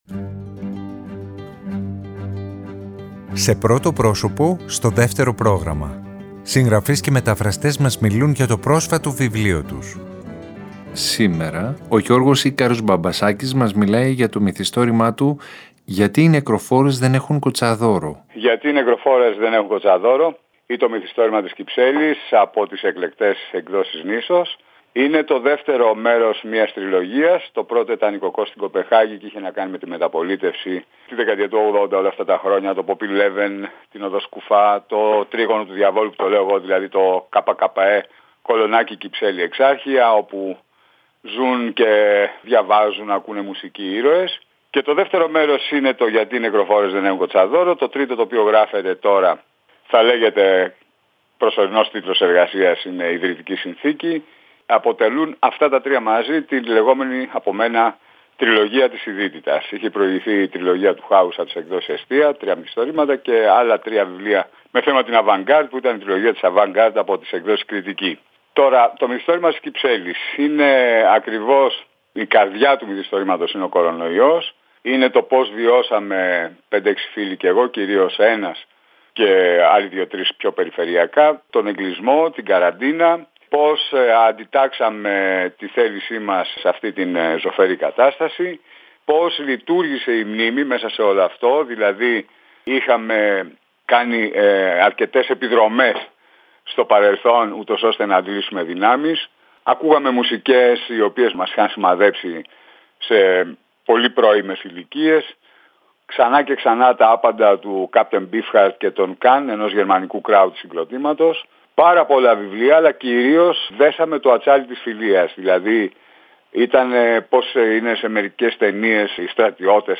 Συγγραφείς και μεταφραστές μιλάνε